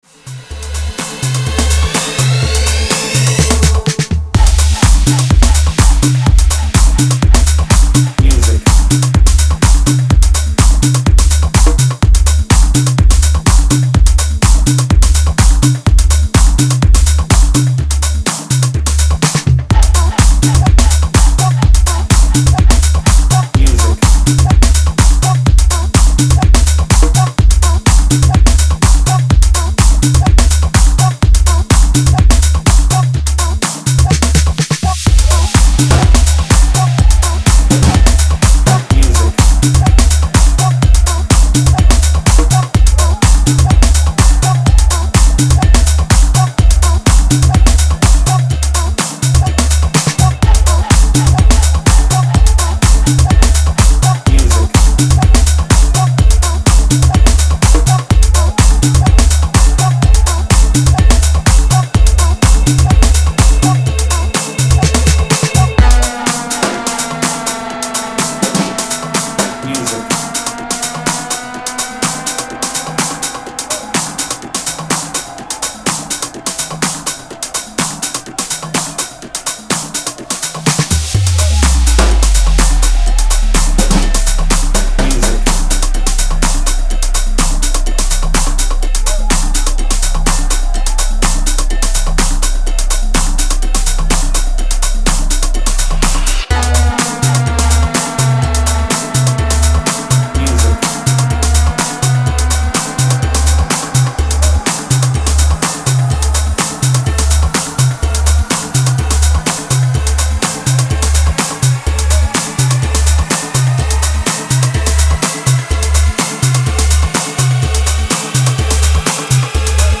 Tech House